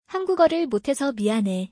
ハングゴルル モッテソ ミアネ